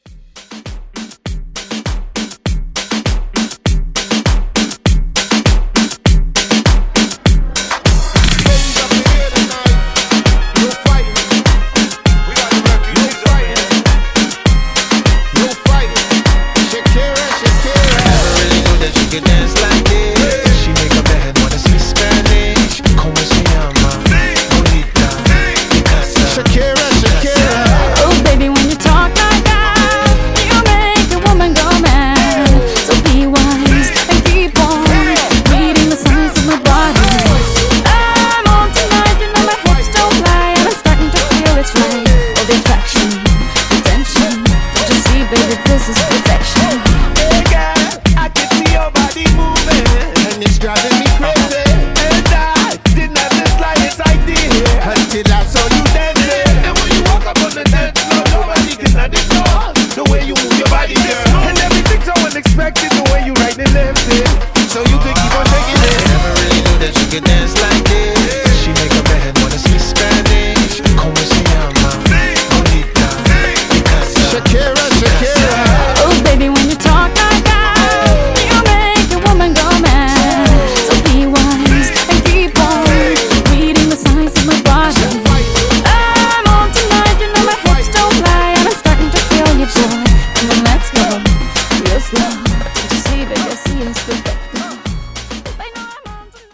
DANCE , MOOMBAHTON , RE-DRUM